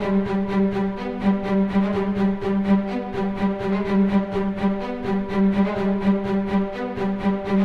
弦乐 50 125 Bpm
Tag: 125 bpm Cinematic Loops Strings Loops 1.29 MB wav Key : Unknown